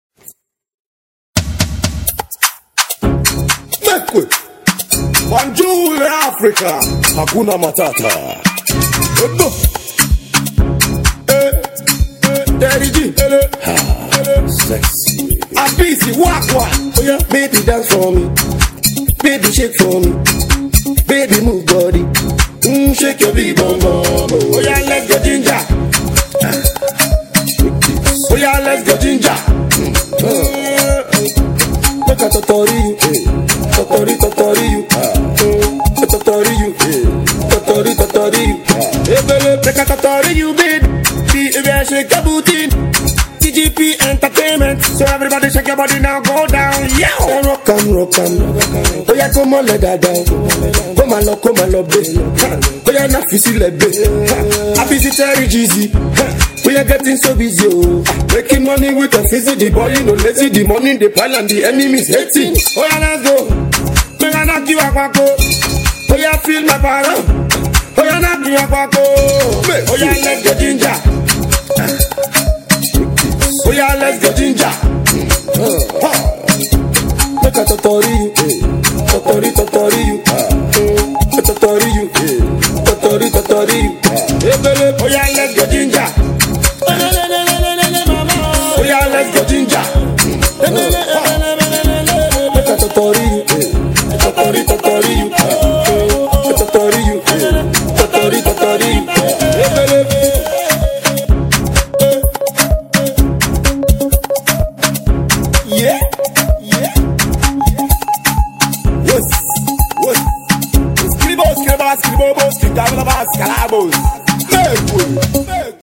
Pop single